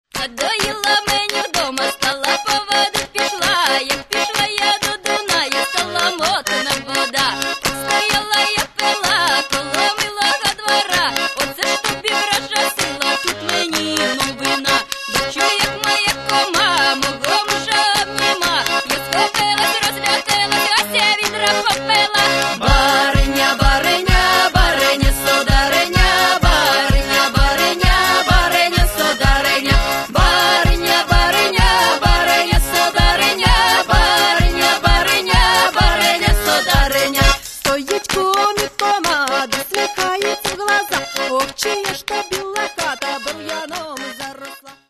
Каталог -> Поп (Легкая) -> Этно-поп
этно-поп-рок